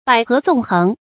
捭阖纵横 bǎi hé zòng héng
捭阖纵横发音